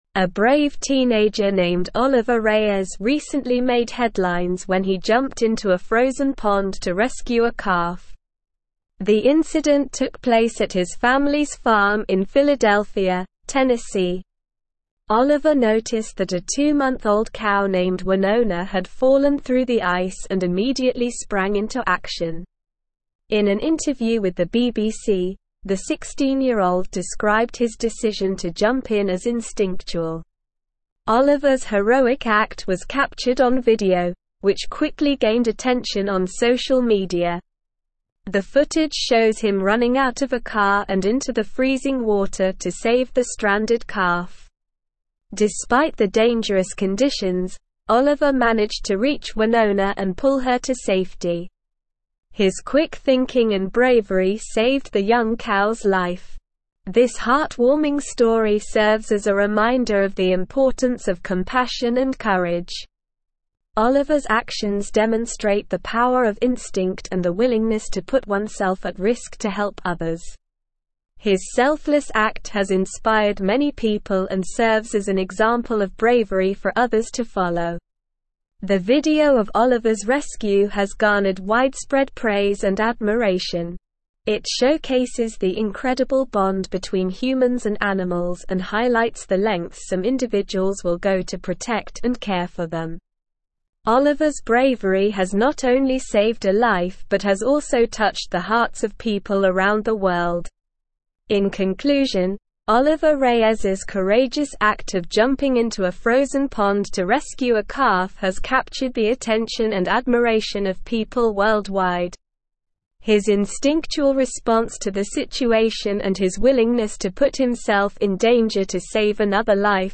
Slow
English-Newsroom-Advanced-SLOW-Reading-Teenager-Rescues-Longhorn-Calf-from-Frozen-Pond.mp3